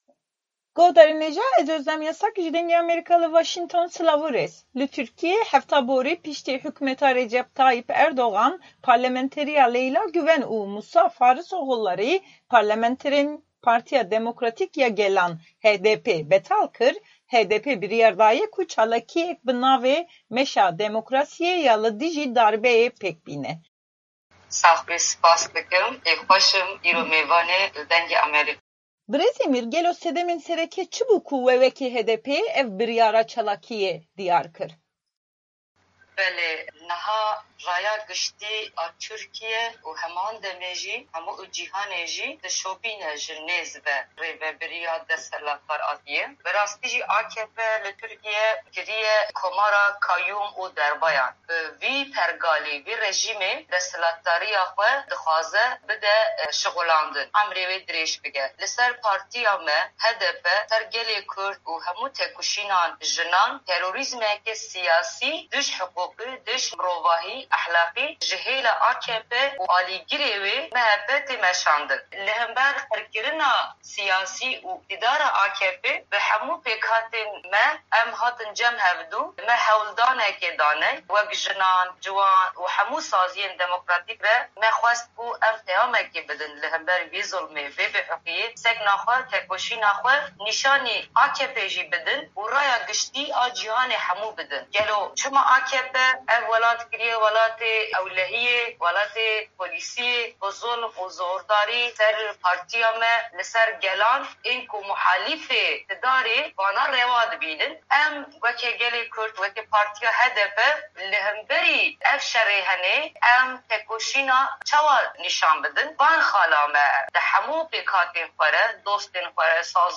Hevpeyvîna bi Parlementerê HDP'ê ya Şirnexe Nuran Îmîr